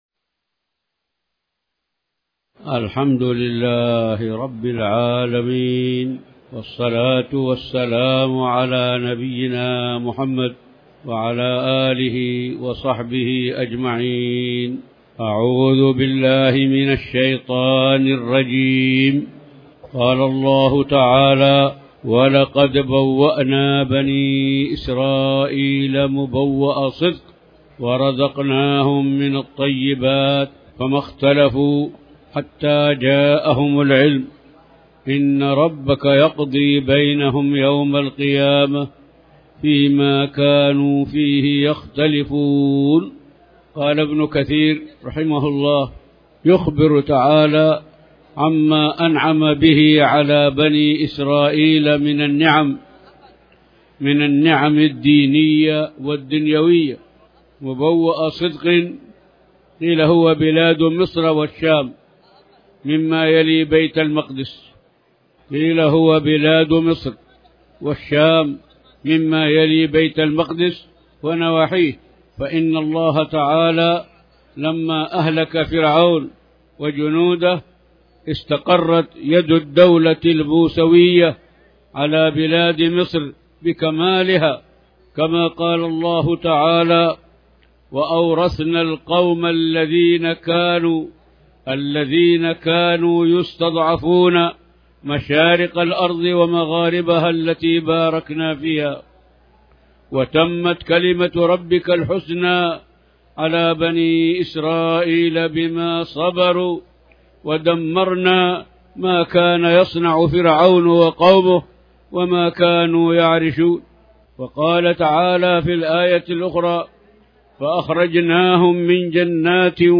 تاريخ النشر ٥ محرم ١٤٤٠ هـ المكان: المسجد الحرام الشيخ